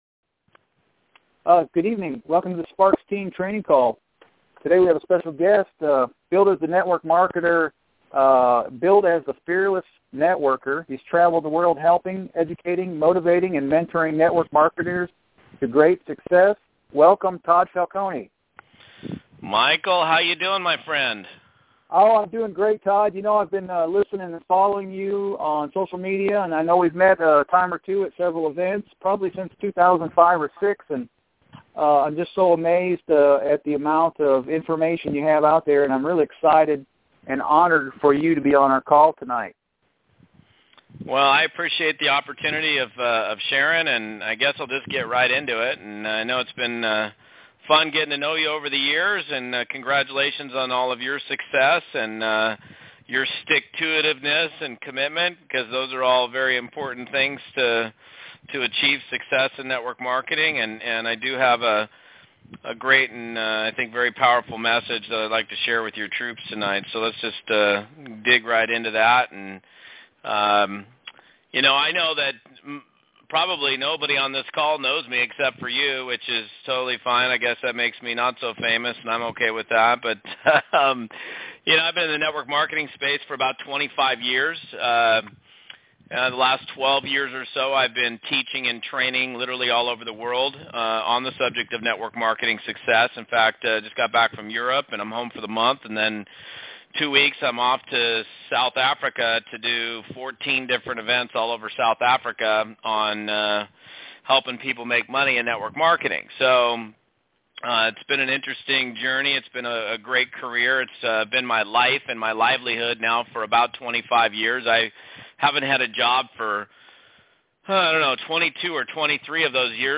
Training Call